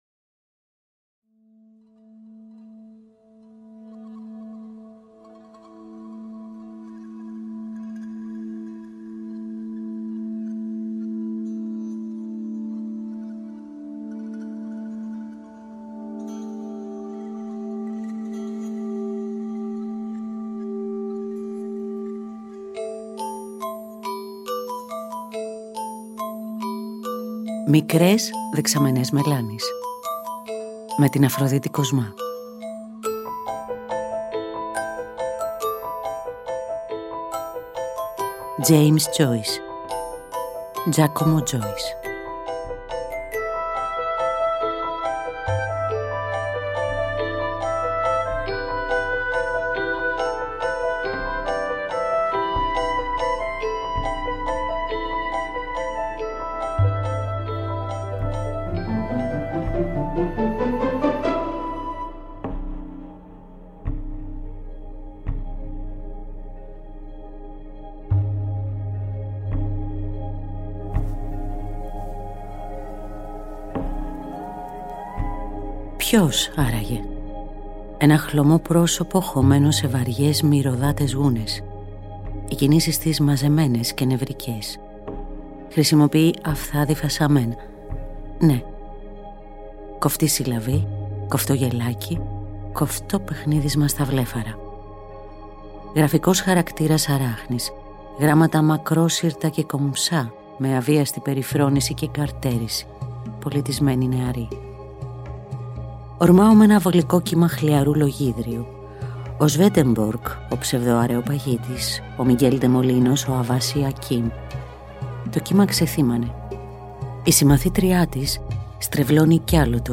αφήγηση